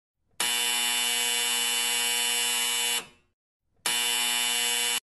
buzzer